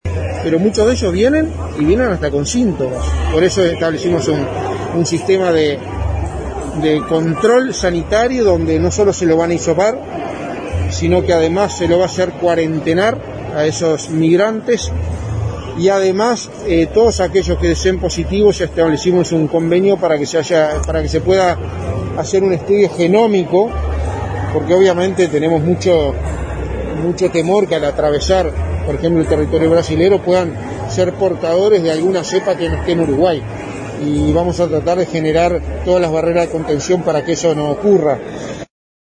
En rueda de prensa recordó que el gobierno americano donó carpas que se utilizarán para hacer un centro de migrantes en Rivera.